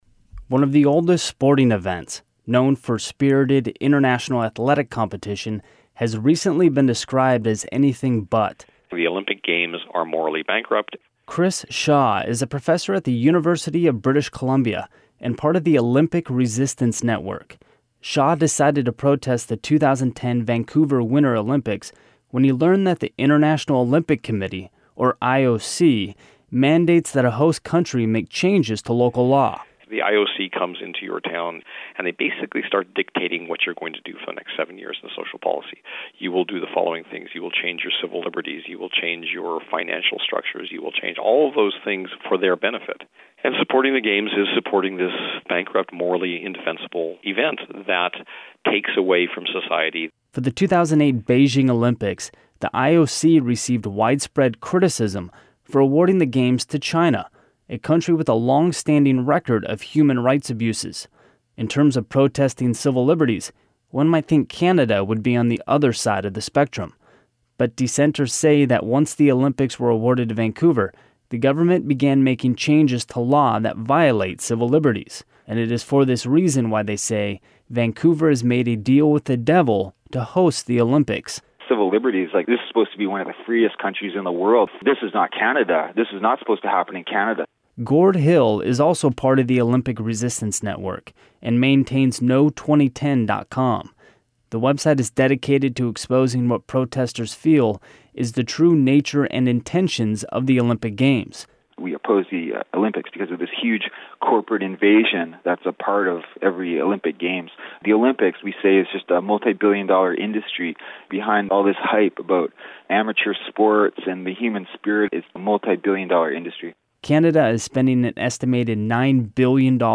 This story originally aired on Community Radio KBCS 91.3 FM in Seattle, WA.